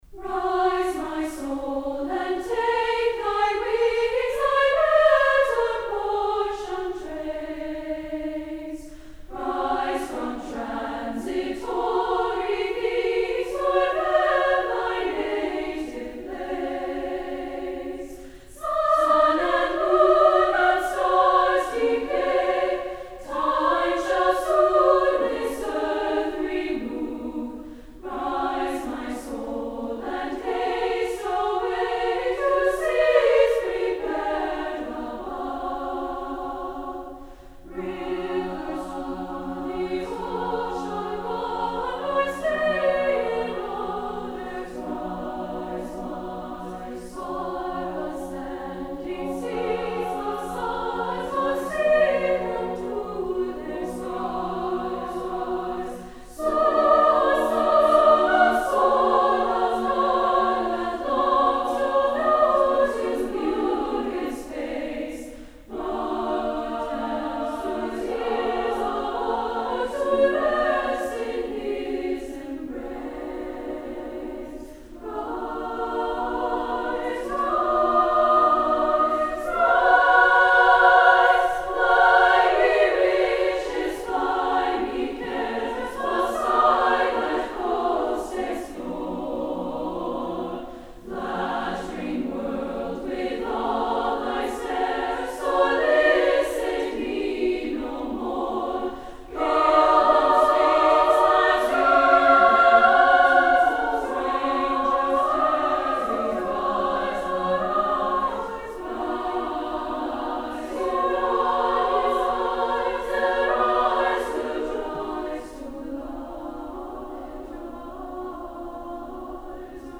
Voicing: SSAA a cappella